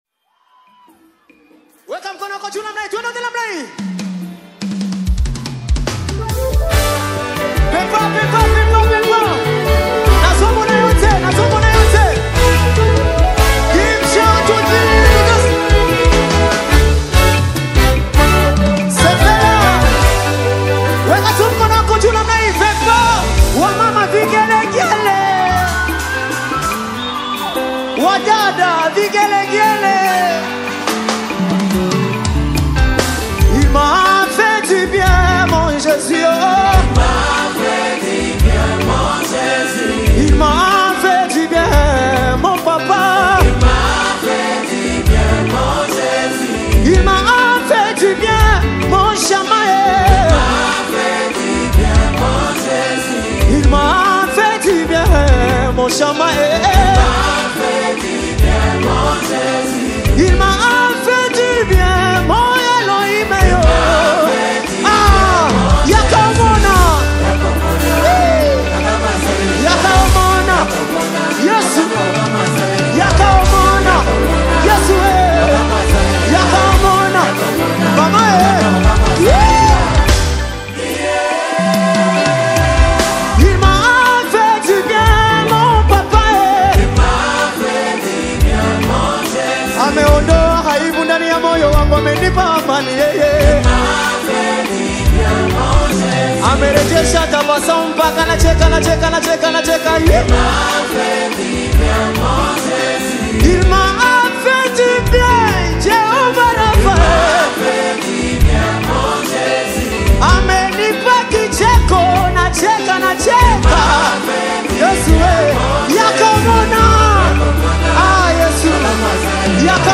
The rhythmically explosive and vocally staggering single